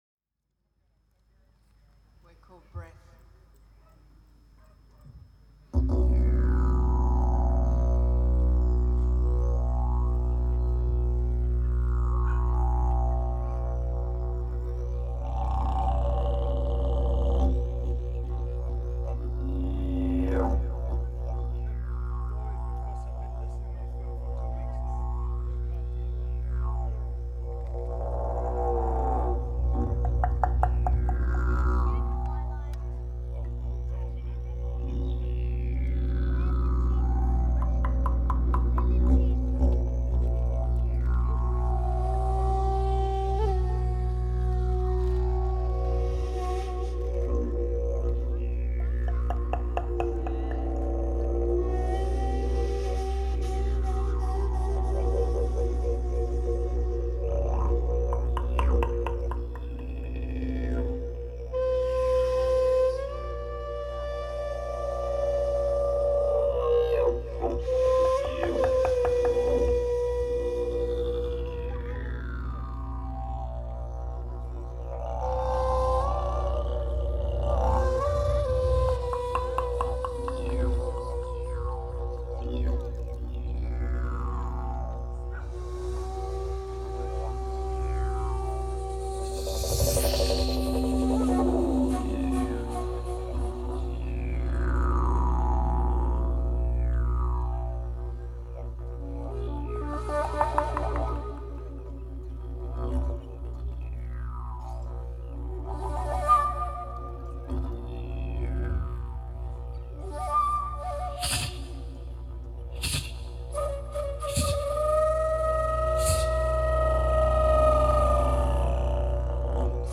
live @ Melbourne Didgeridu & Cultural Festival – 2013
shakuhachi (flutes)
didgerido, bell
beatbox, chant, bells
Intro – trio